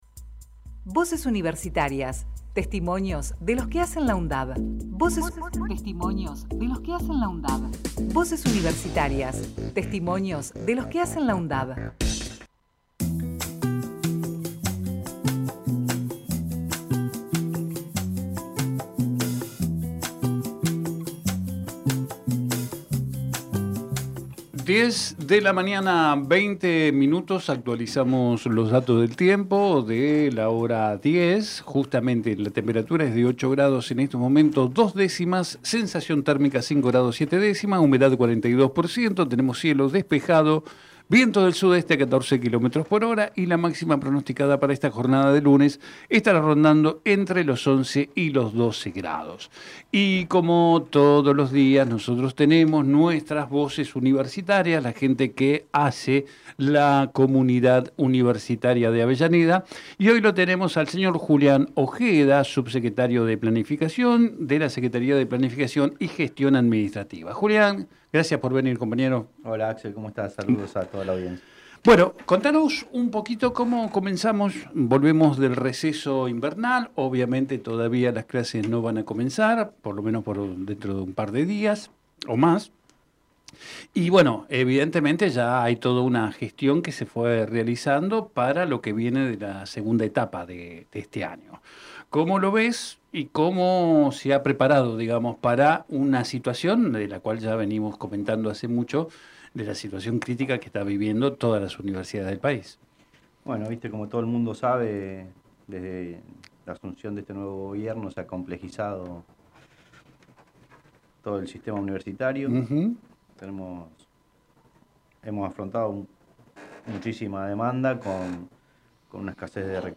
Compartimos entrevista realizada en "Territorio Sur"